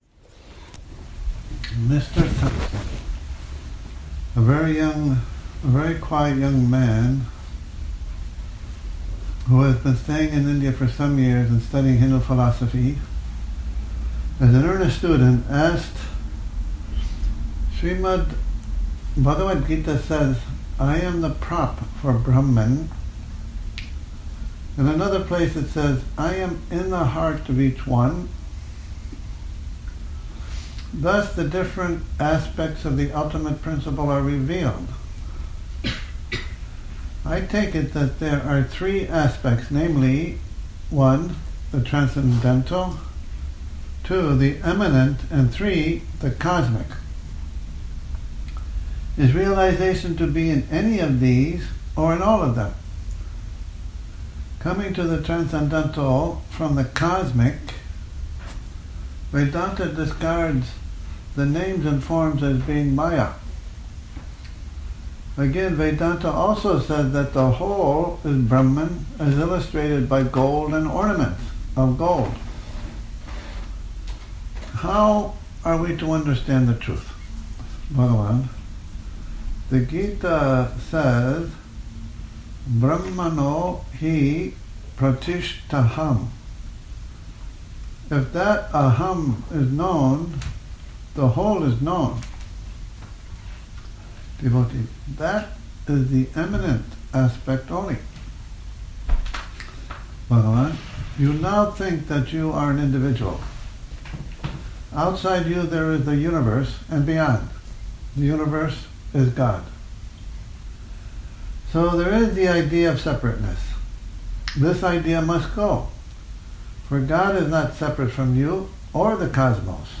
Morning Reading, 07 Nov 2019